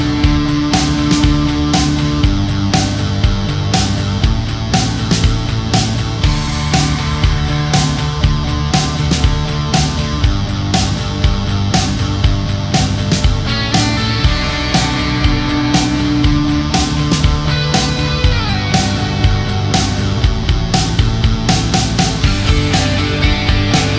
no Backing Vocals Indie / Alternative 4:48 Buy £1.50